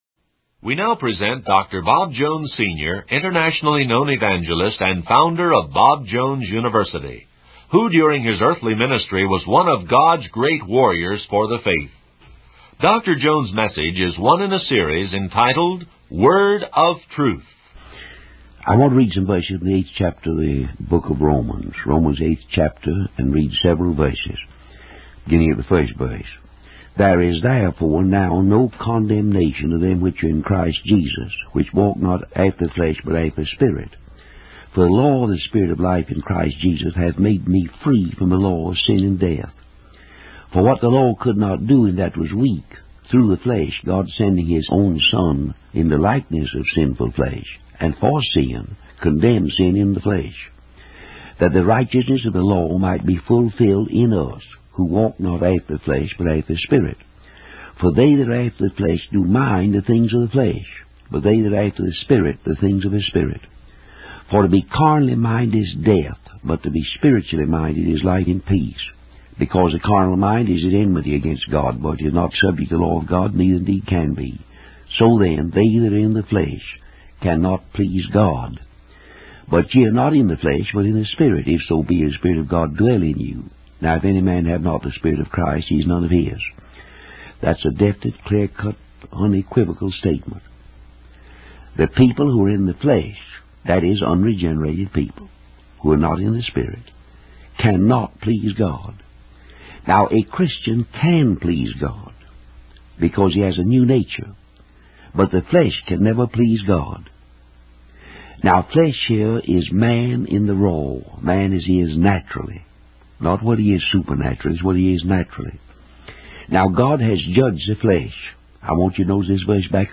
In this sermon, Dr. Bob Jones Sr. focuses on the message of Romans 8:1-2, emphasizing that there is no condemnation for those who are in Christ Jesus and walk according to the Spirit. He highlights that as believers, we are freed from the law of sin and death through the law of the Spirit of life in Christ Jesus.